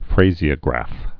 (frāzē-ə-grăf)